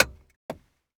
clock_ticking_01.wav